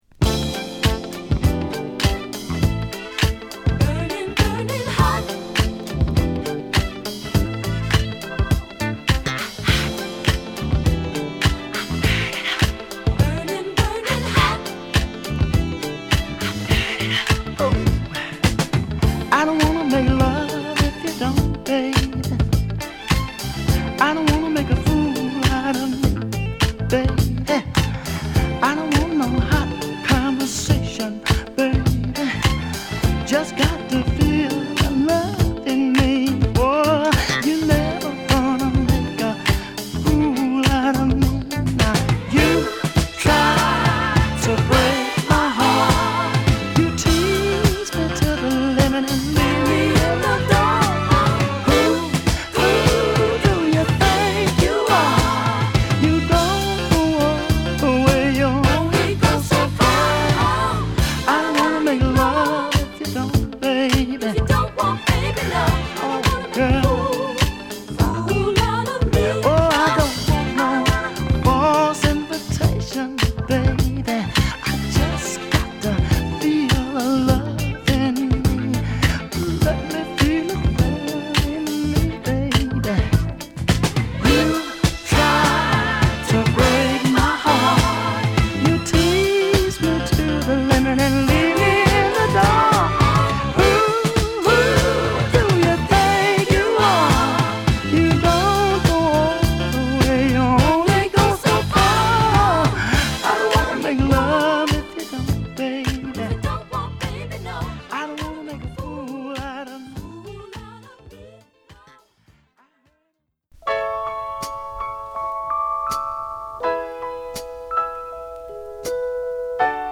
メンフィスストリングス／ホーンをバックにしっとりと歌うスロウ